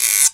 1ST-GUIRO -L.wav